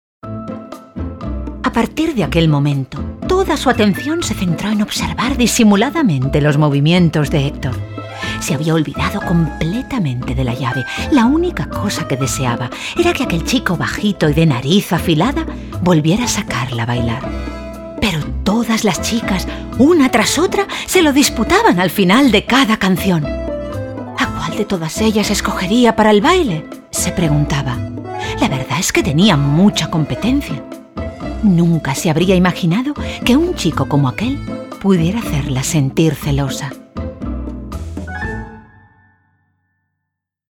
kastilisch
Sprechprobe: Sonstiges (Muttersprache):